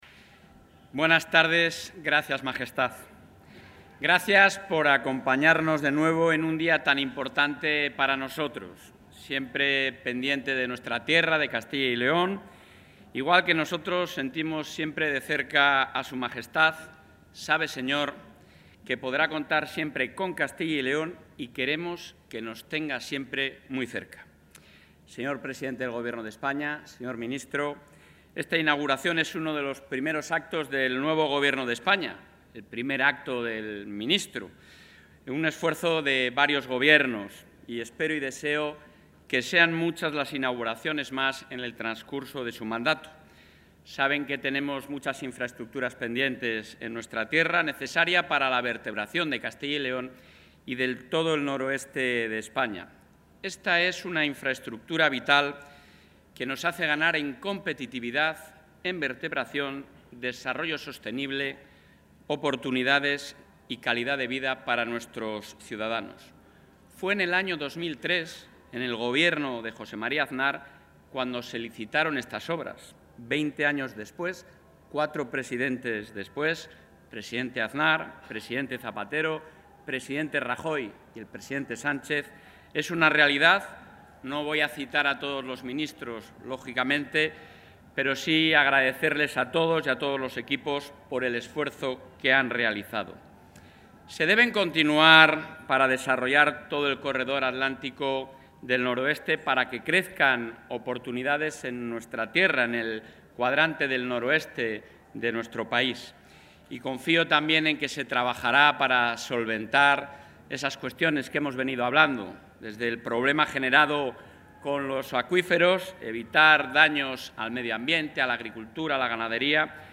En Oviedo, tras finalizar el trayecto de inauguración de la Variante de Pajares, el presidente de la Junta de Castilla y León, Alfonso...
Intervención del presidente.